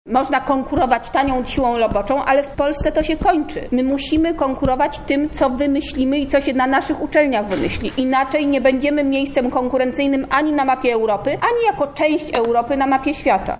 Minister podczas spotkania z samorządowcami podkreśliła również wagę konkurencyjności.